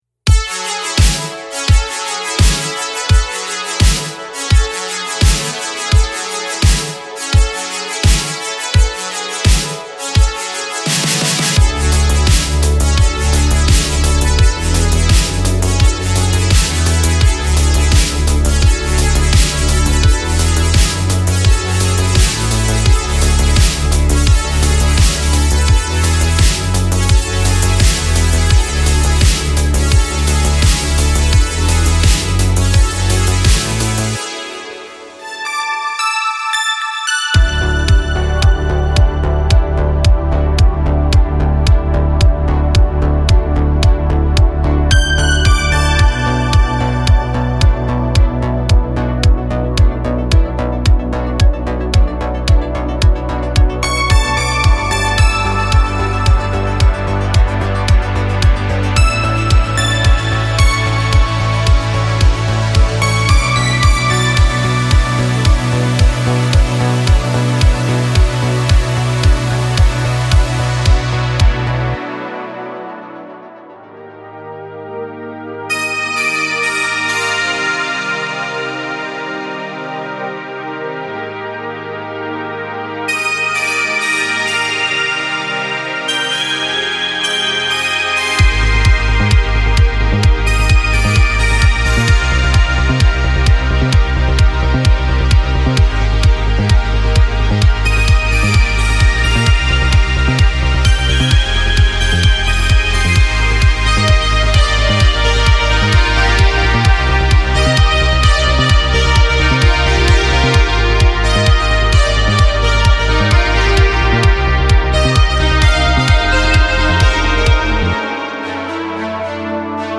它是70年代和80年代声音的全新外观。
SPIRERETRO SYNTHWAVE2特别喜欢喜欢模拟贝司，主音，弹奏和打击垫的声音的制作人。
们还为您创作了鼓舞人心的琶音，打击乐和效果器，这对于您创建未来的合成波音乐很有帮助。